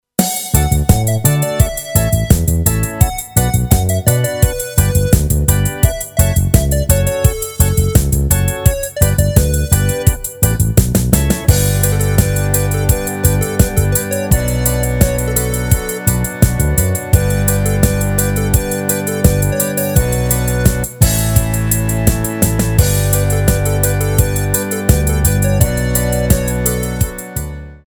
Rubrika: Pop, rock, beat
Karaoke
HUDEBNÍ PODKLADY V AUDIO A VIDEO SOUBORECH